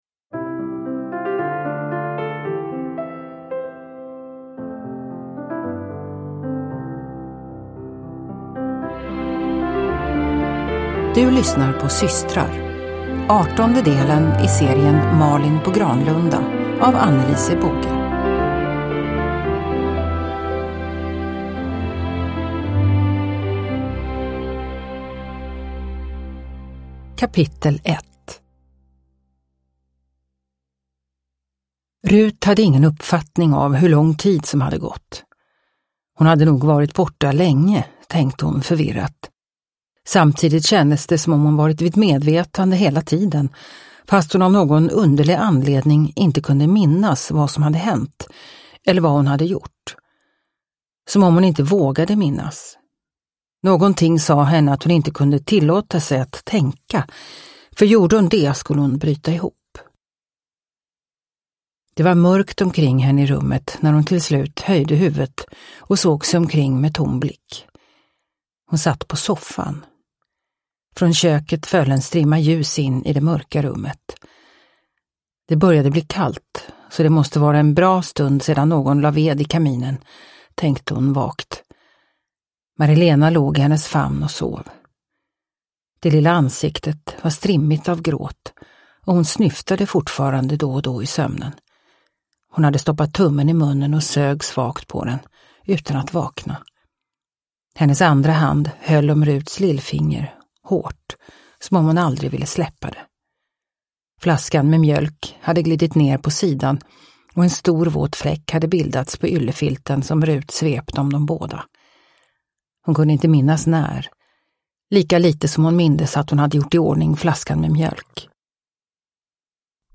Systrar – Ljudbok – Laddas ner